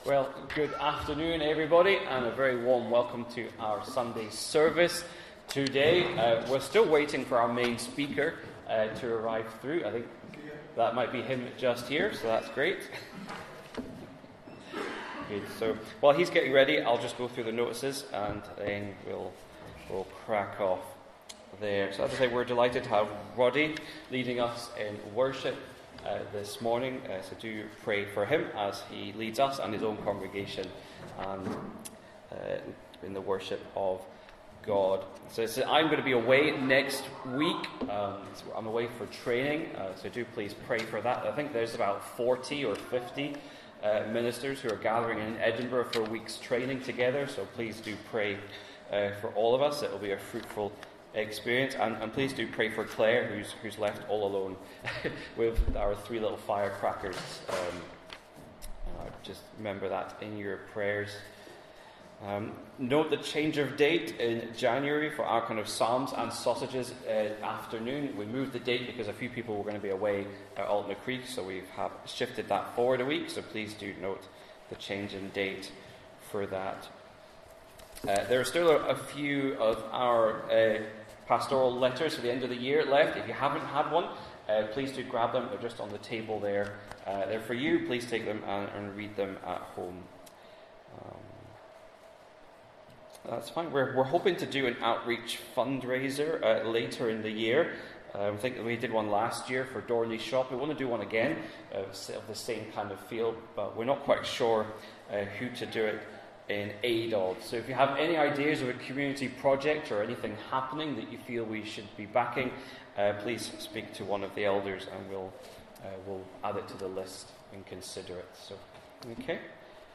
12-Noon-Service-.mp3